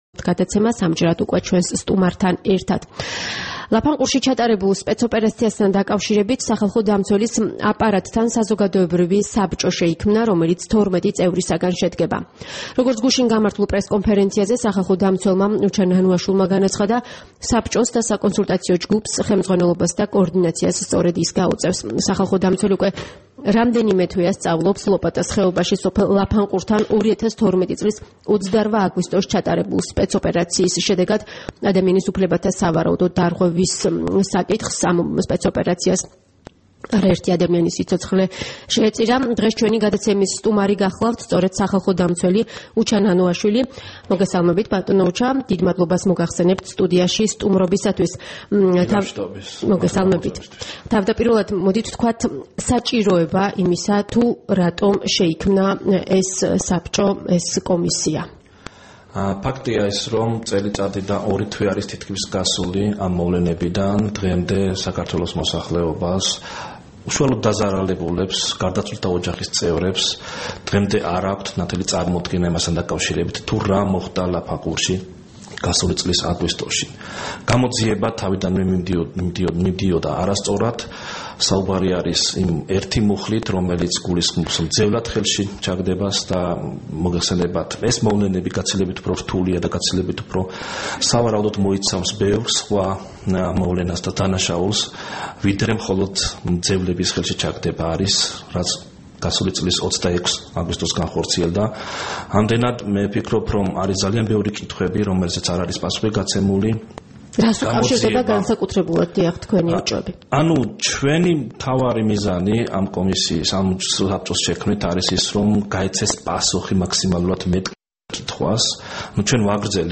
სტუმრად ჩვენს ეთერში: უჩა ნანუაშვილი
საუბარი უჩა ნანუაშვილთან